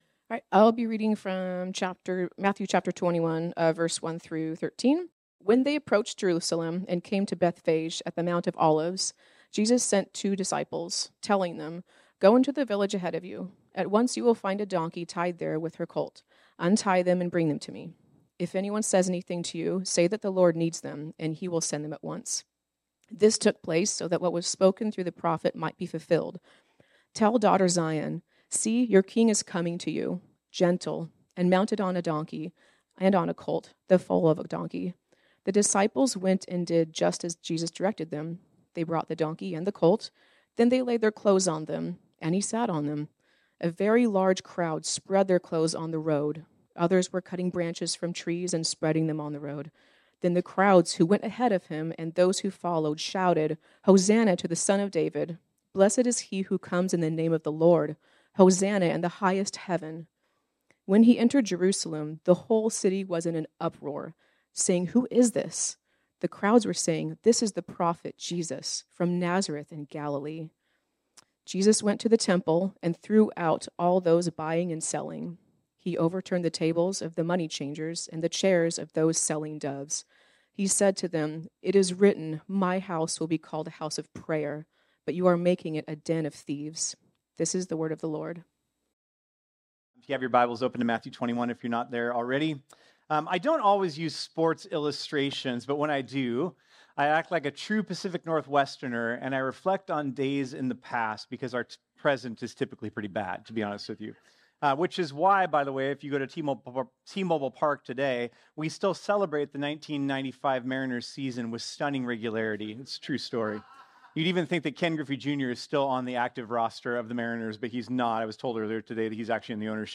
This sermon was originally preached on Sunday, September 22, 2024.